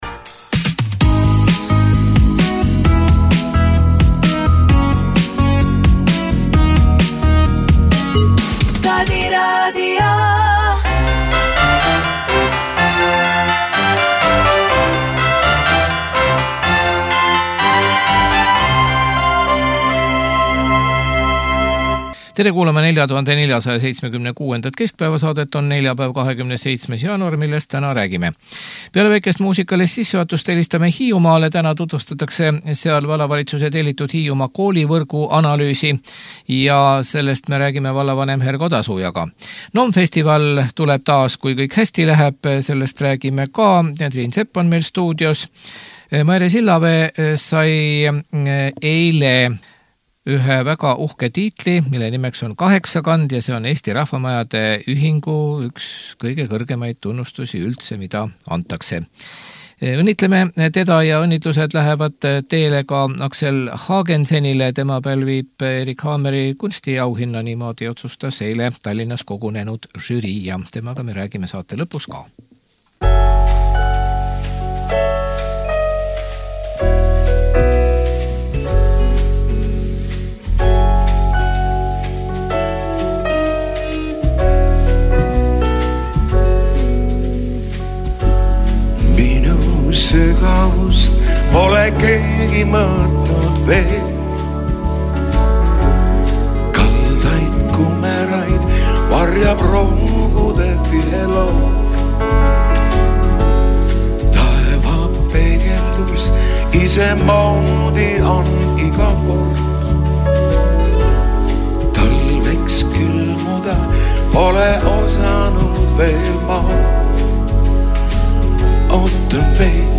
Täna tutvustatakse Hiiumaal vallavalitsuse tellitud Hiiumaa koolivõrgu analüüsi. Telefonil on vallavanem Hergo Tasuja.